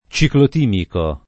vai all'elenco alfabetico delle voci ingrandisci il carattere 100% rimpicciolisci il carattere stampa invia tramite posta elettronica codividi su Facebook ciclotimico [ © iklot & miko ] agg. e s. m. (med.); pl. m. -ci